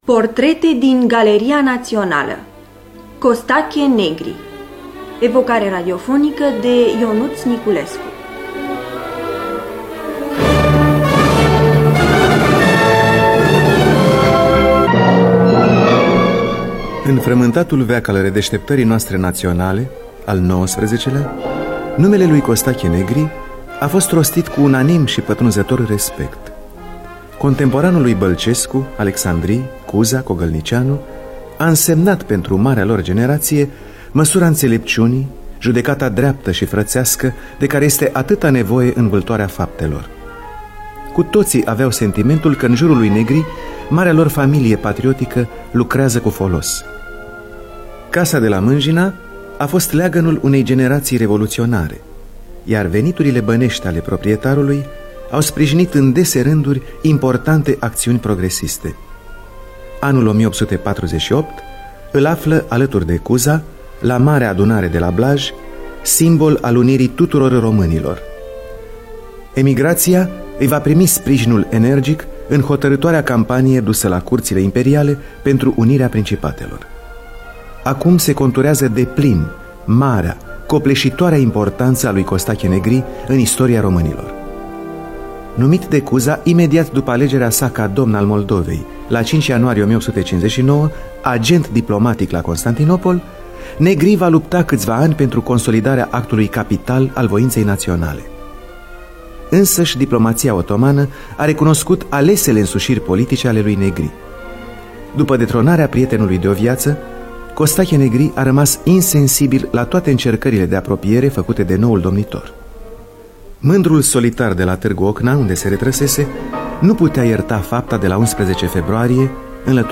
Scenariu radiofonic de Ionuț Niculescu.